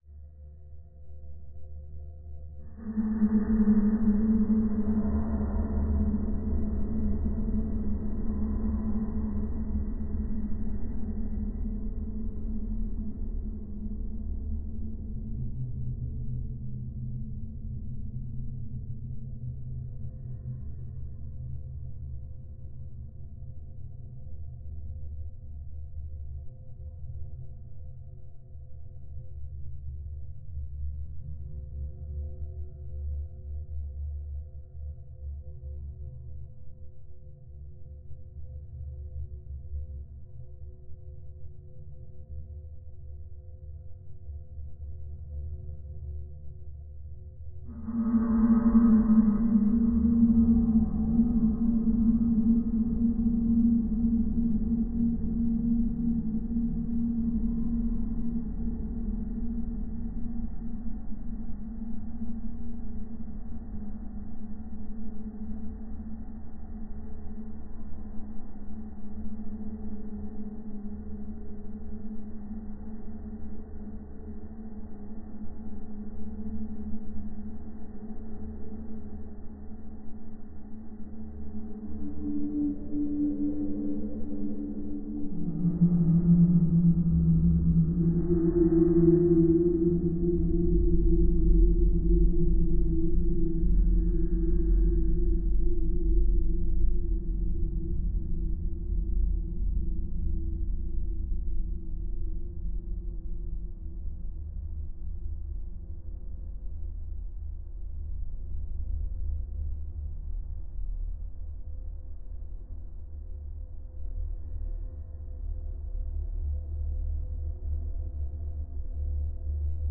ambient_background.mp3